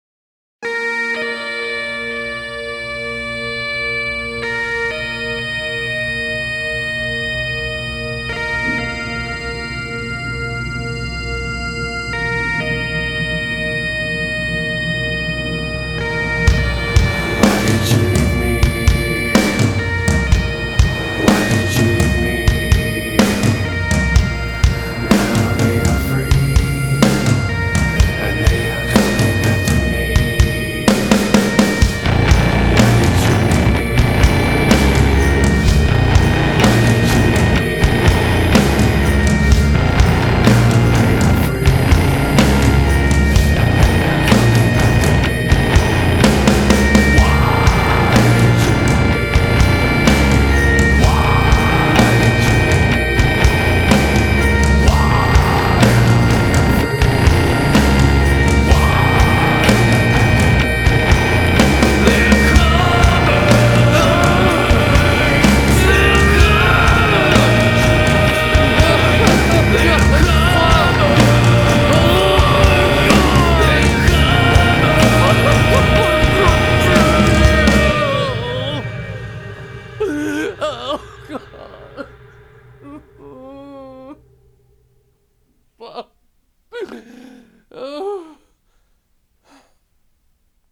Nu Metal, Alternative Metal